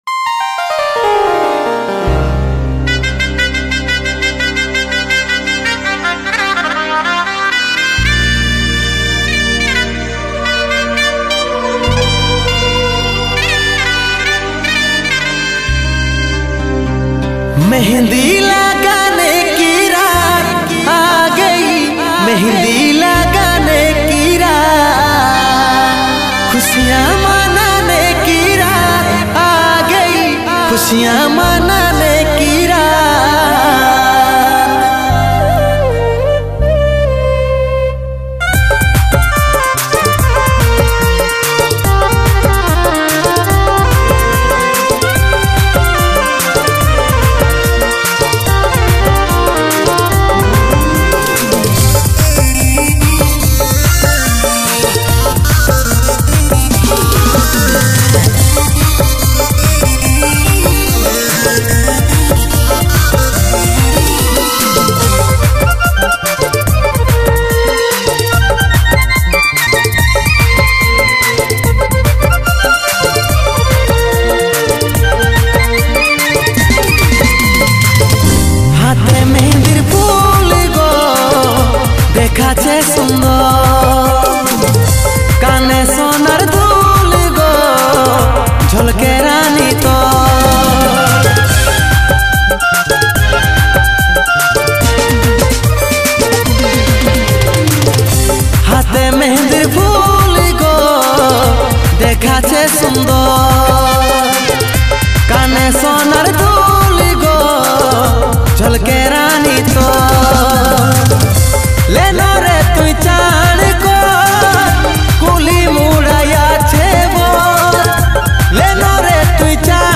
Purulia Gana